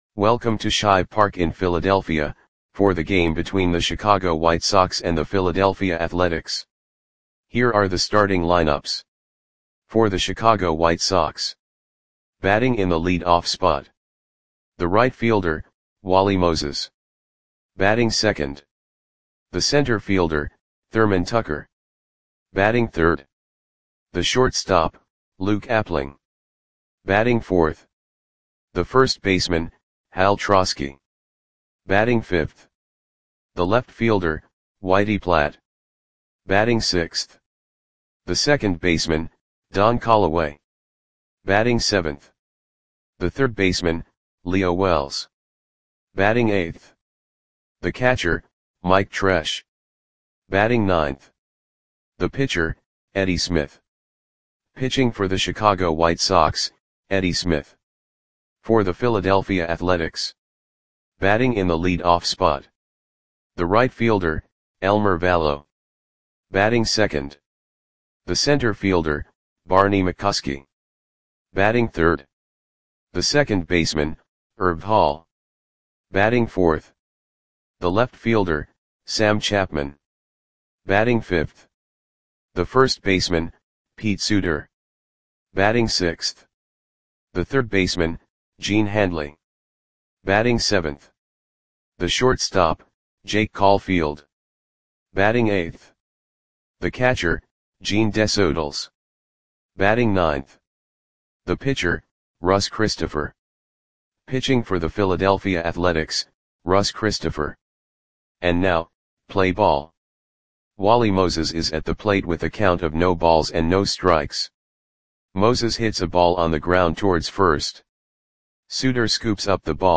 Audio Play-by-Play for Philadelphia Athletics on June 11, 1946
Click the button below to listen to the audio play-by-play.